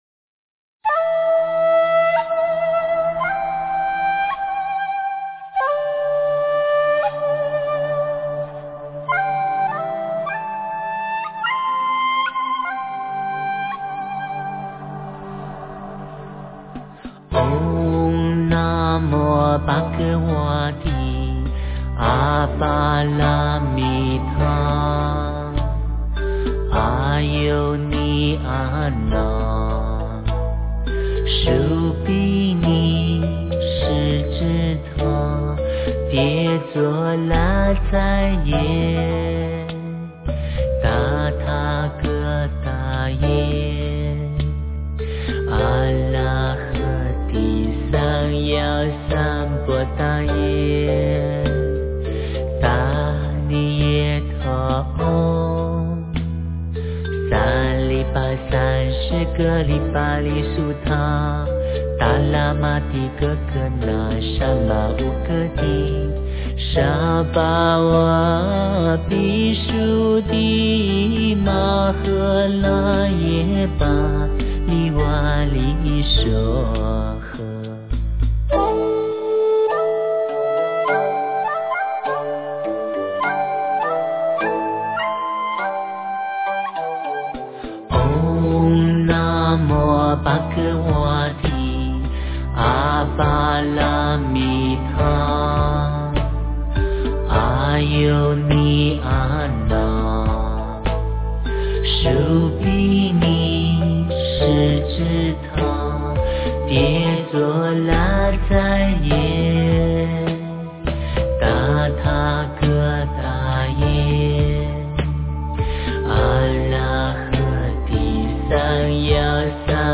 真言
标签: 佛音真言佛教音乐